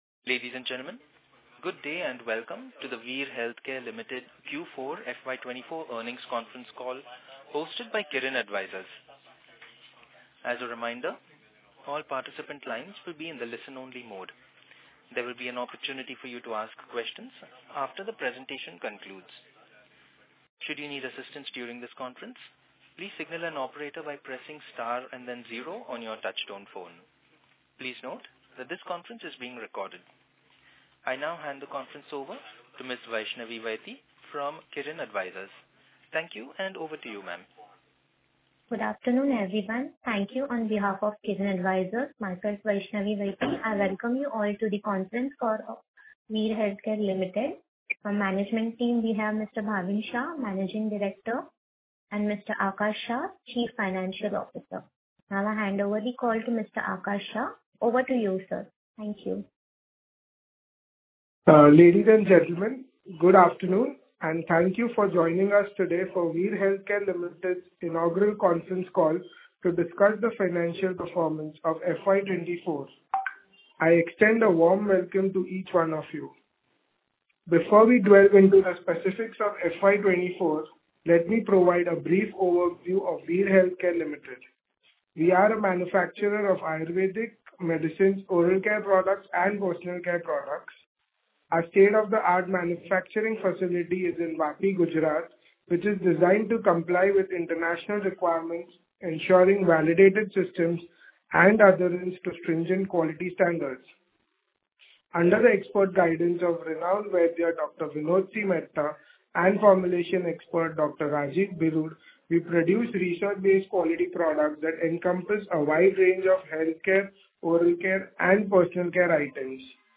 Earning Concall Audio - Q4 FY24
Earning-Concall-Audio-of-Veerhealth-Care-Limited-Q4-FY24.mp3